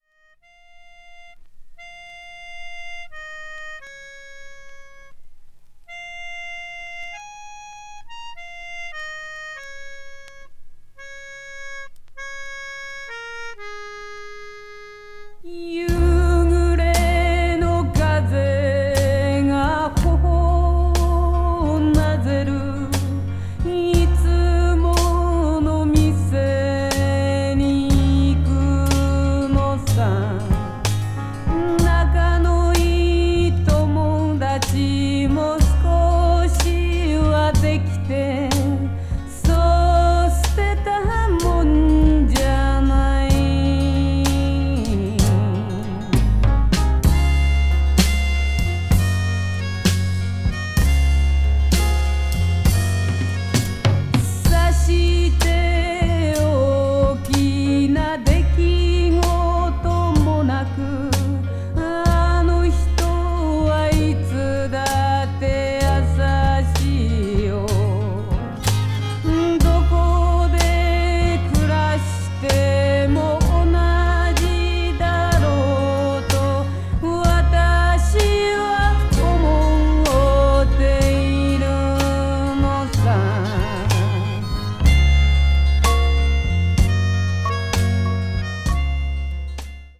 media : EX+/EX+(わずかにチリノイズが入る箇所あり)
blues rock   folk rock   jazz vocal   psychedelic rock